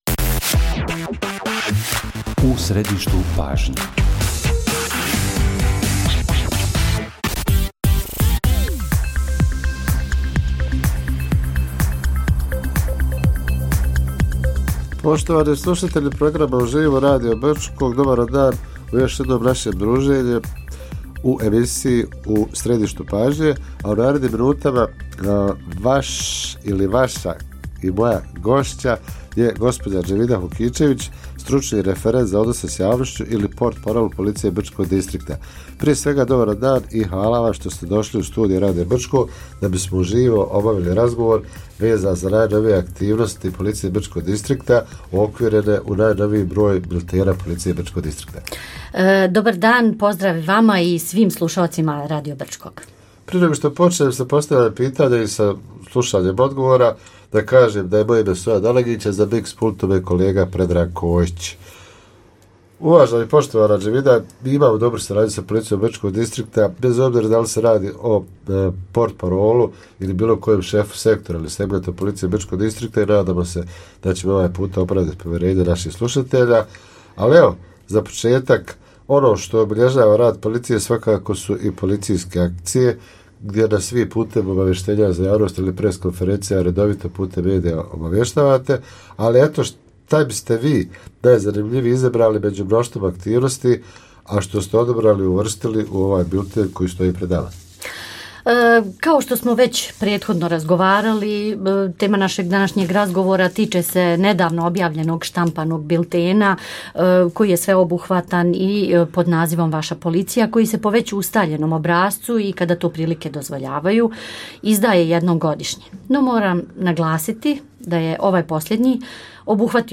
Gost emisije “U središtu pažnje”